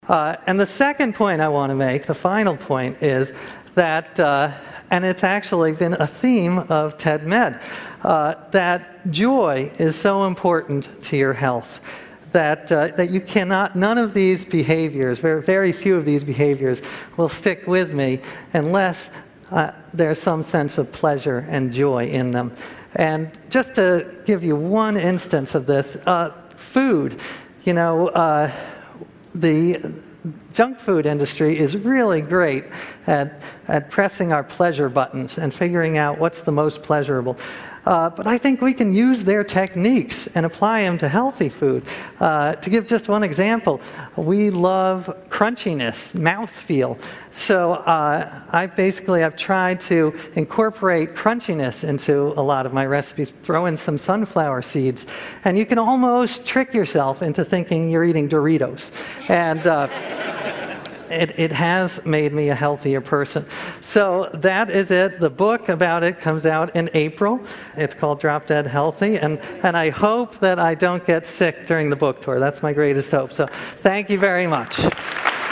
TED演讲：为什么健康的生活方式几乎把我害死(6) 听力文件下载—在线英语听力室